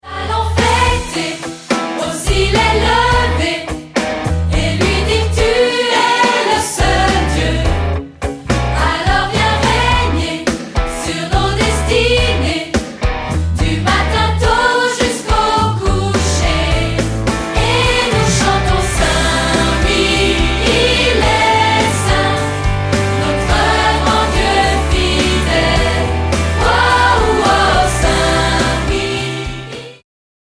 • Chants de louange pour églises, familles et autres
• Chorale et solistes